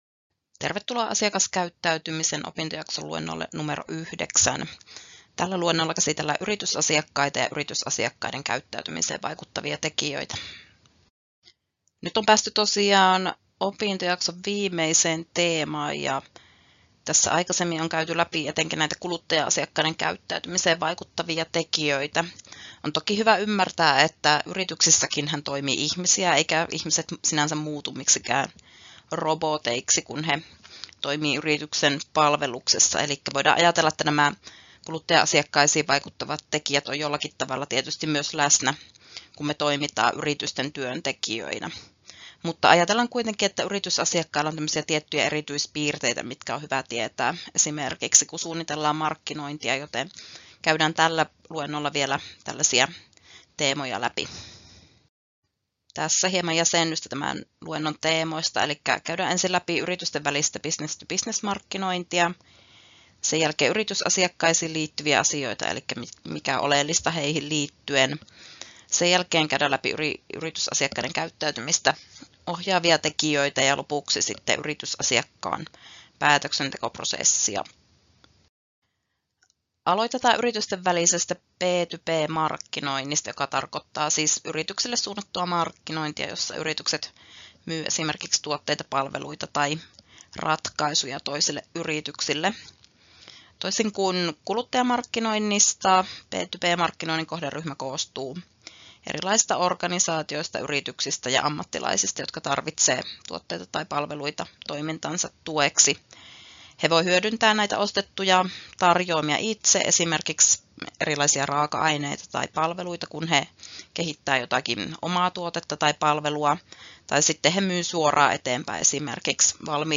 Luento 9: Yritysasiakkaat — Moniviestin